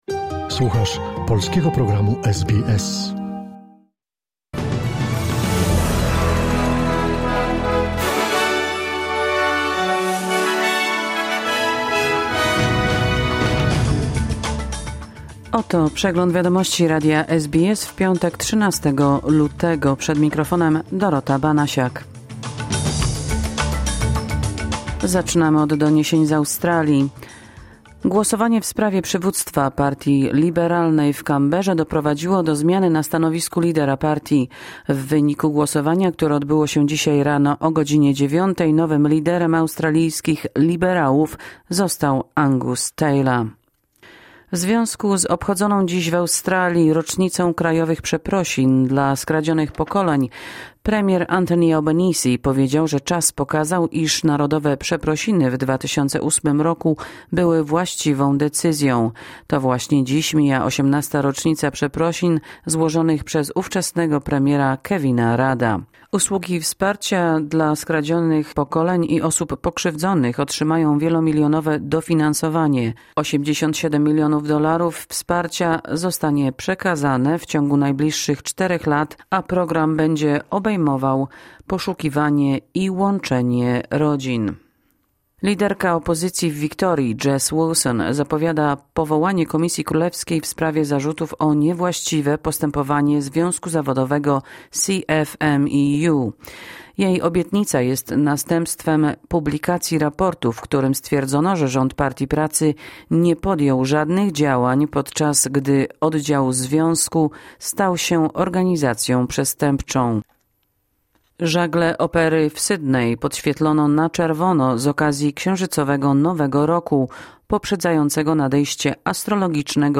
Wiadomości 13 lutego SBS News Flash